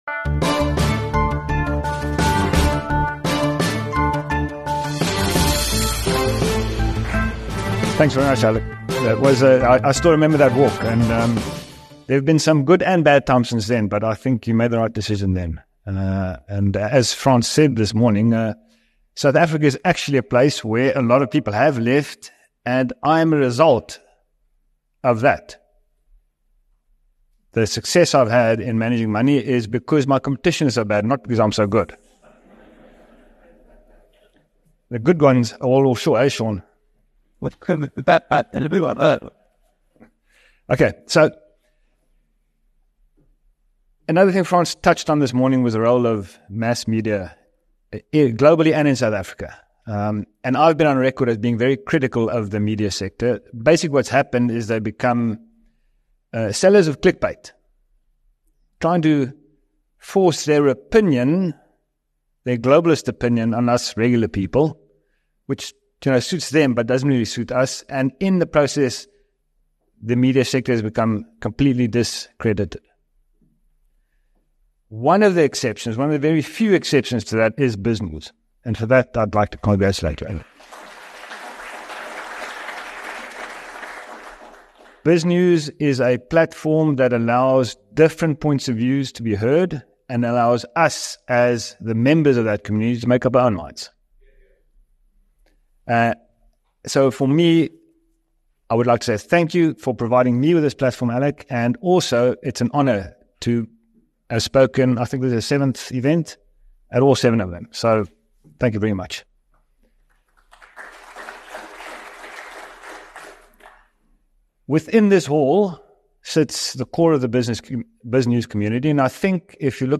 keynote session